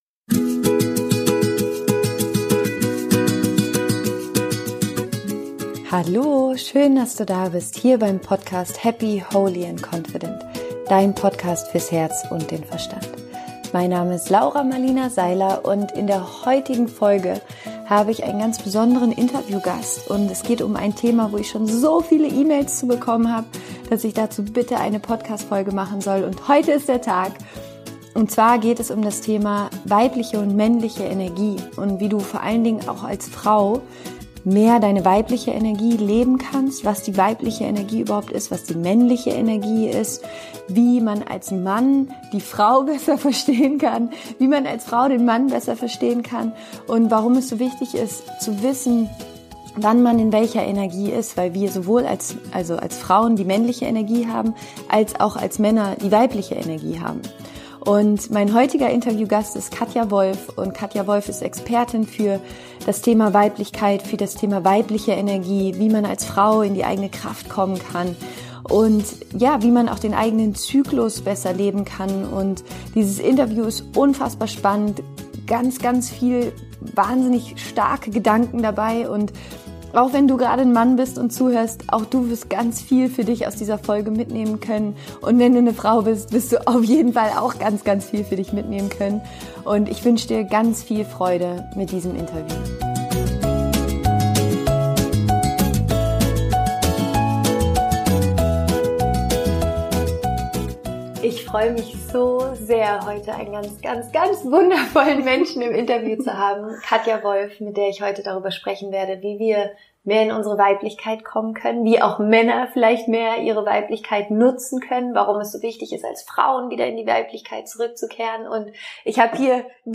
Interview Special